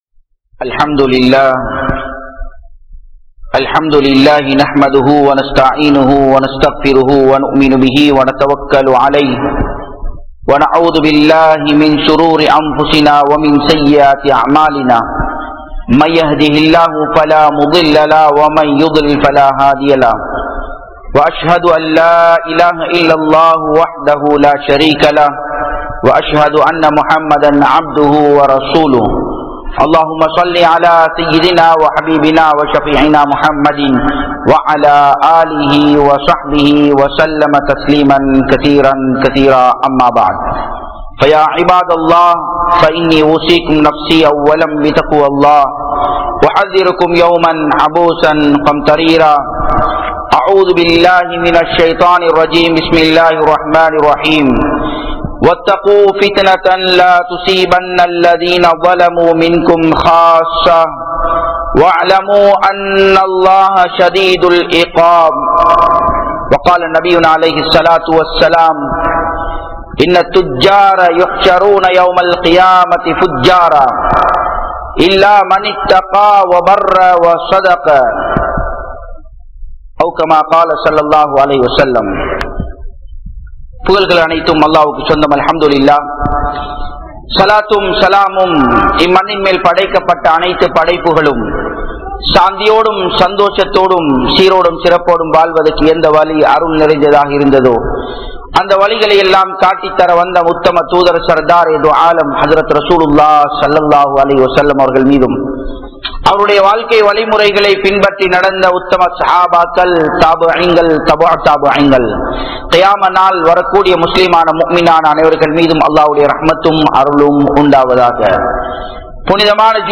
Moasadien Thandanai (மோசடியின் தண்டனை) | Audio Bayans | All Ceylon Muslim Youth Community | Addalaichenai